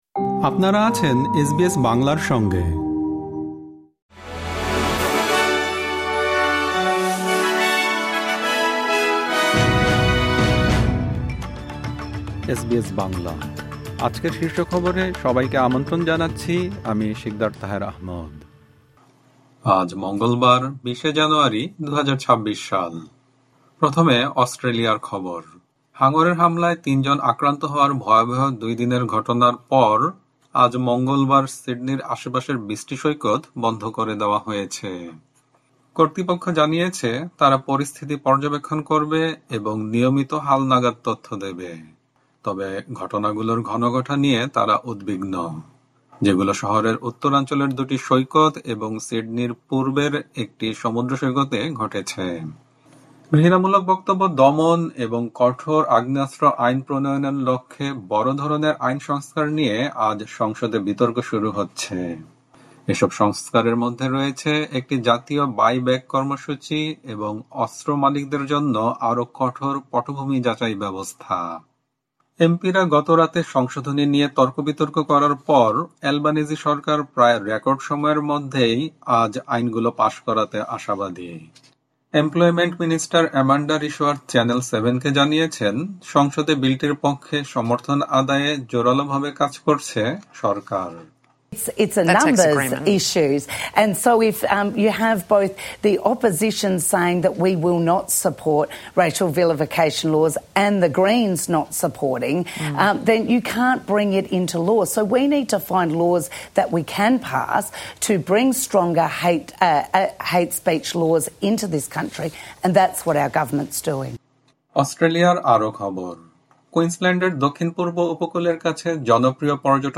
অস্ট্রেলিয়ার জাতীয় ও আন্তর্জাতিক সংবাদের জন্য আজ ১৯ জানুয়ারি, ২০২৬ এর এসবিএস বাংলা শীর্ষ খবর শুনতে উপরের অডিও-প্লেয়ারটিতে ক্লিক করুন।